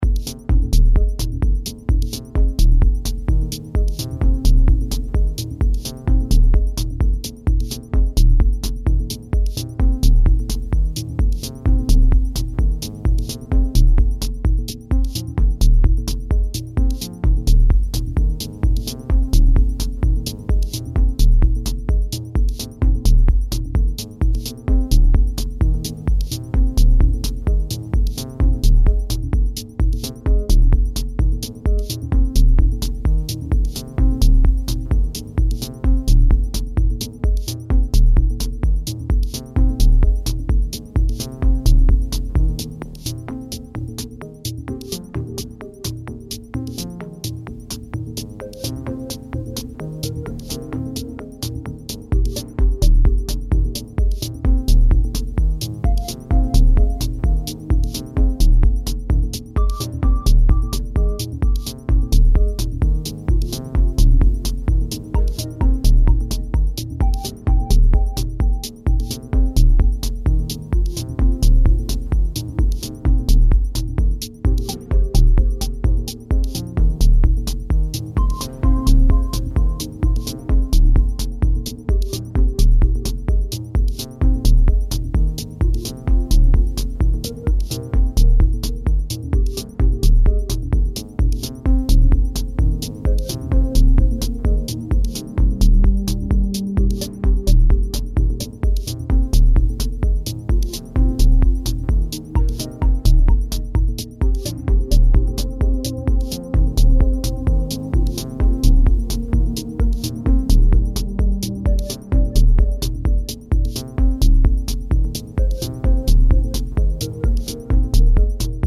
explores insular and inward moods with great deftness